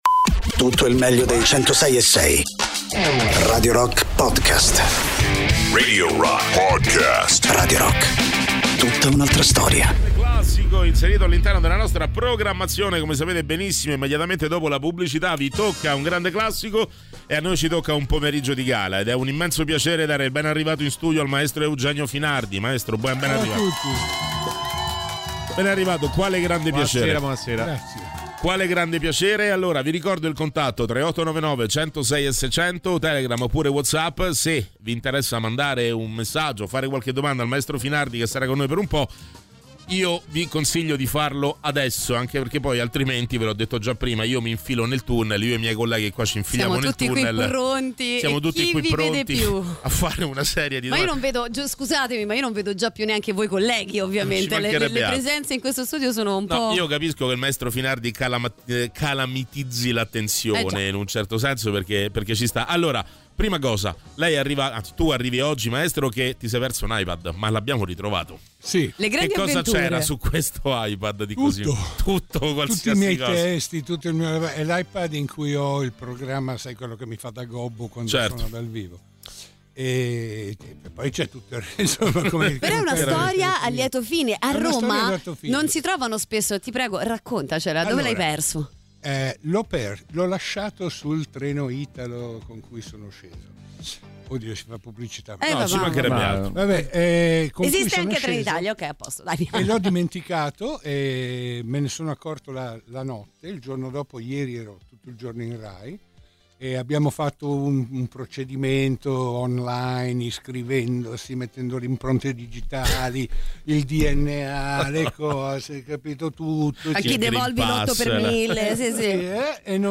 Interviste: Eugenio Finardi (22-05-25)